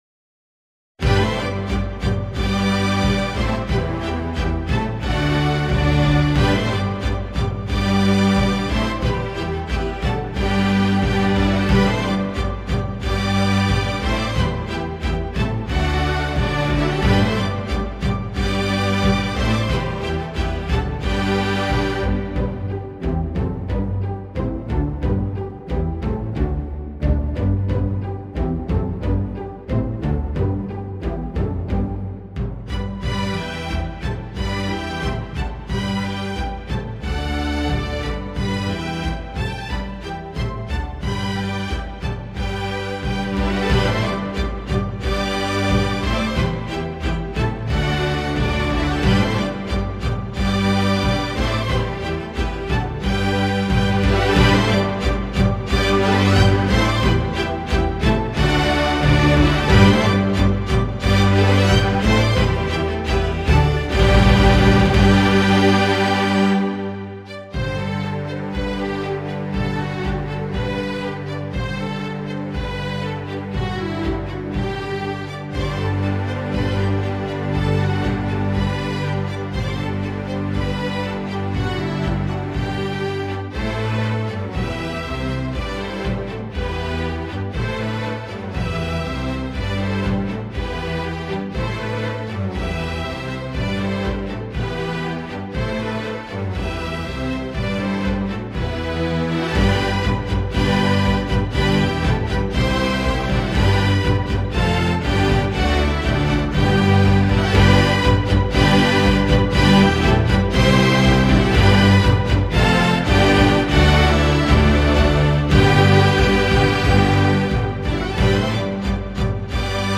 A vibrant arrangement for string orchestra